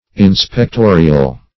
Meaning of inspectorial. inspectorial synonyms, pronunciation, spelling and more from Free Dictionary.
Search Result for " inspectorial" : The Collaborative International Dictionary of English v.0.48: Inspectorial \In`spec*to"ri*al\, a. Of or pertaining to an inspector or to inspection.